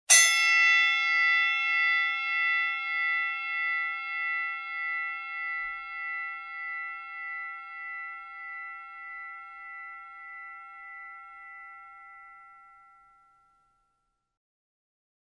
Звуки звона
Звонок в боксерском ринге